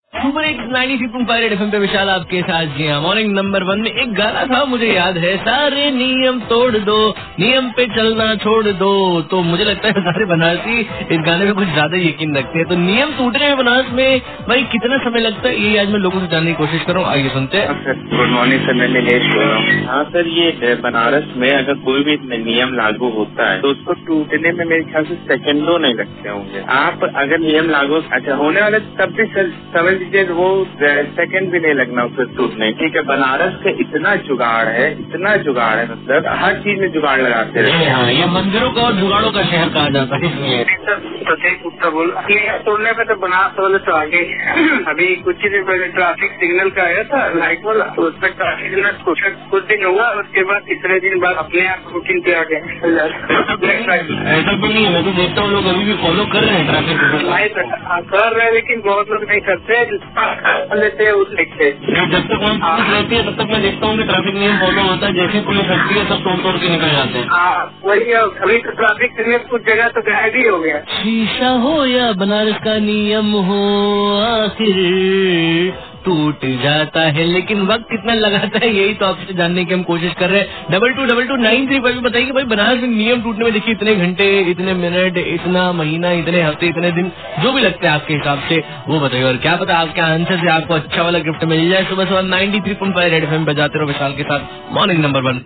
caller